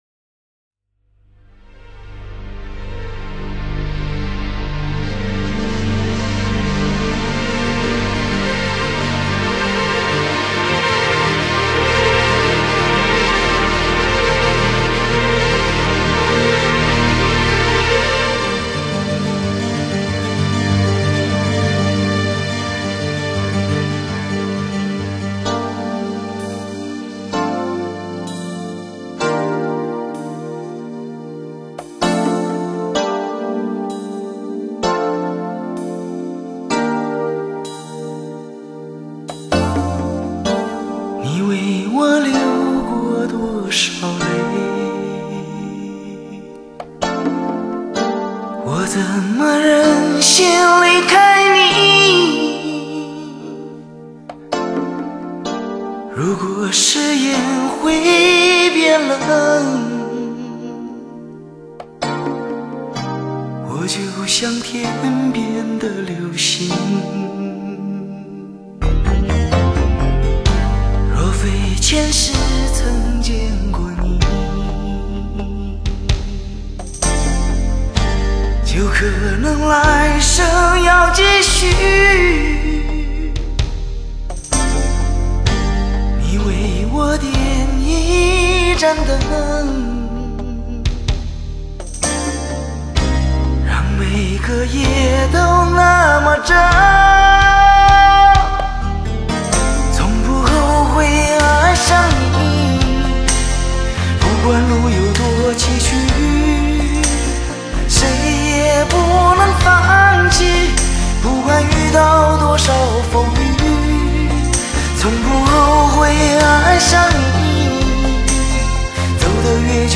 黑胶CD兼容黑胶的高保真和CD的低噪音 开创靓声新纪元
可在CD机上和汽车音响上听到LP黑胶密文唱片的仿真音质，感受那种愉悦的发烧韵味